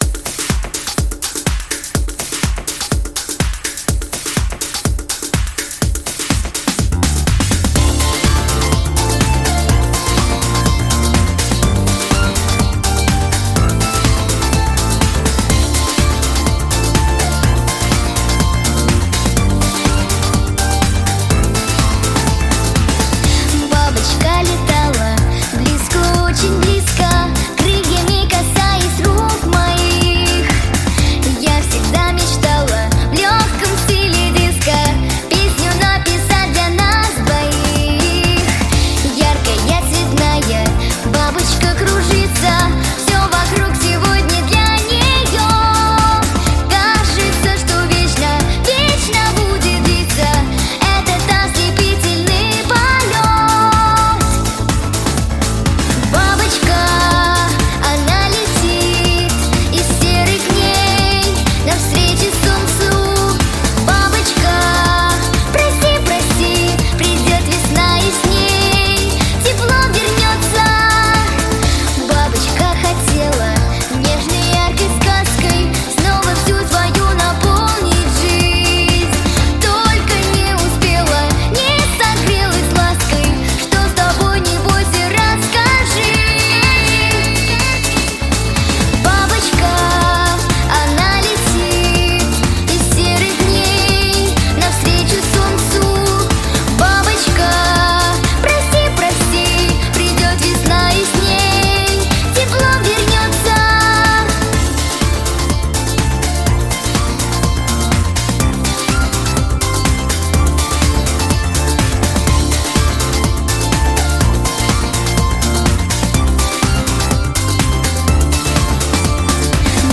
• Категория: Детские песни
Детская песня